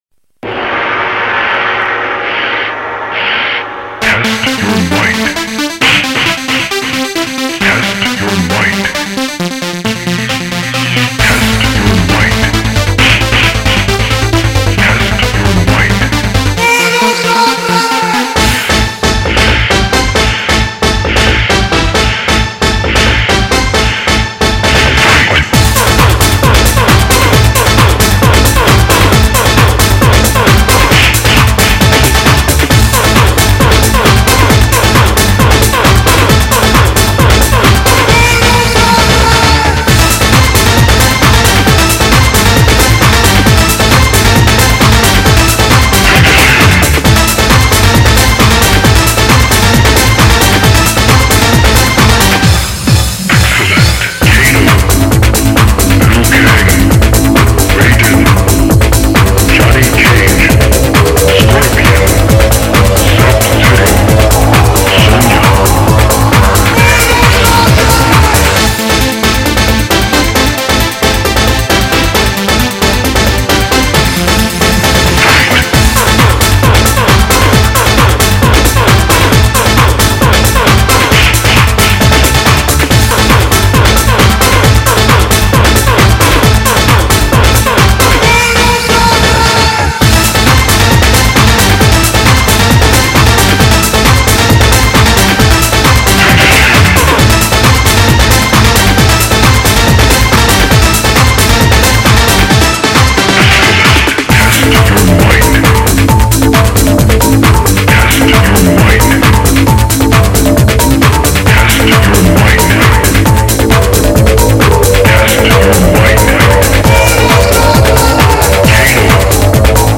هیجان و انرژی بی‌نظیری را به مخاطبان القا کند